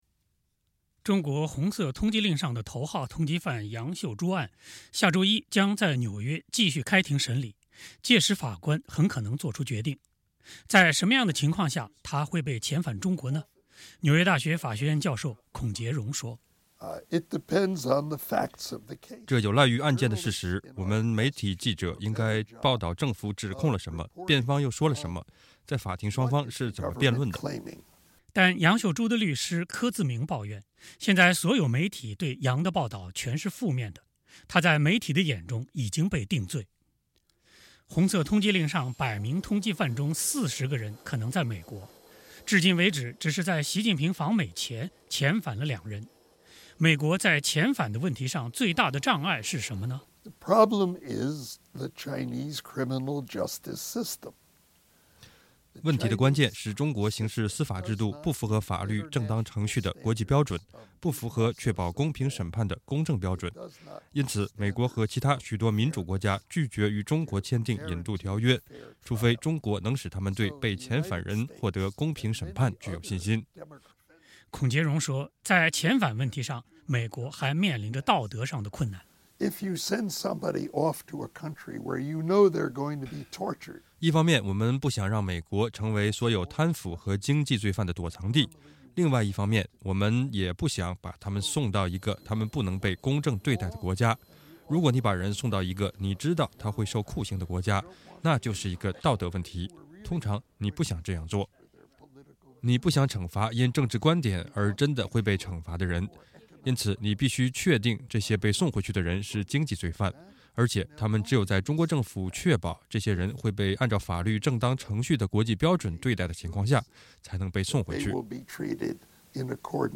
美国的中国法律问题权威、纽约大学法学院教授孔杰荣，星期二接受美国之音专访，对上述问题做出了回答。